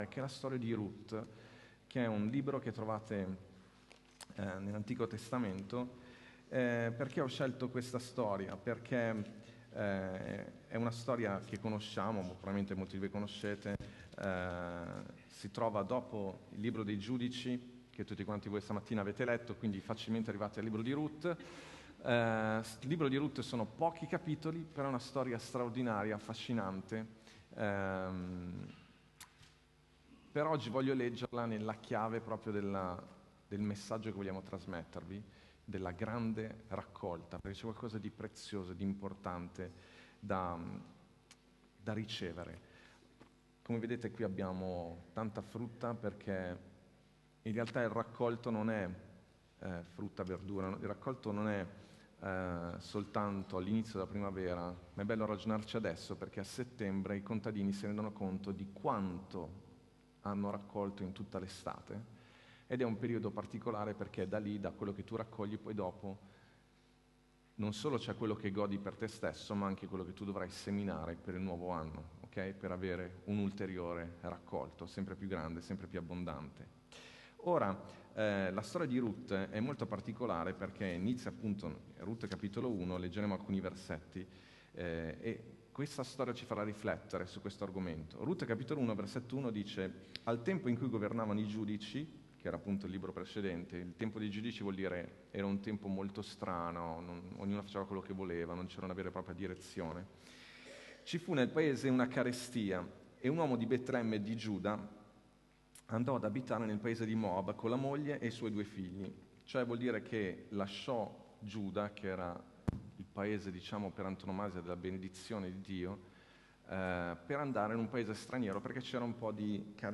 Ascolta la predicazione: La grande raccolta - Chiesa Vita Nuova